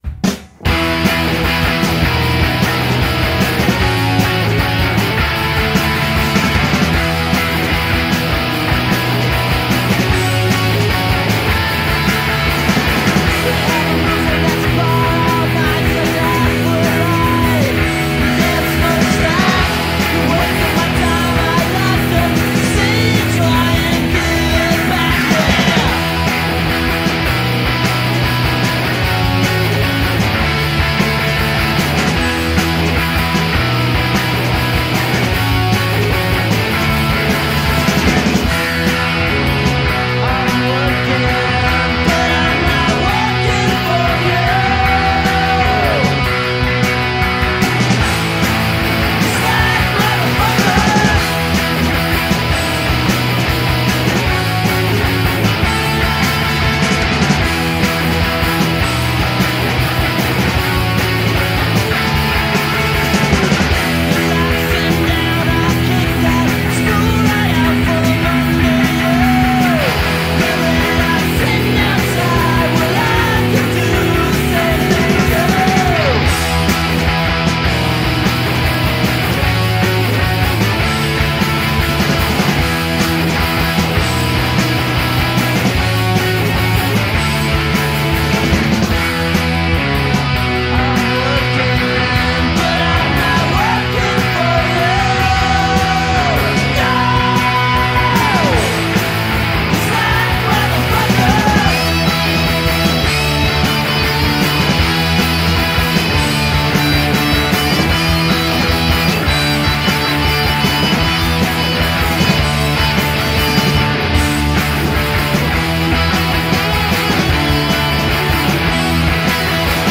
Revisitons la discographie d'un groupe de rock alternatif aussi culte que méconnu...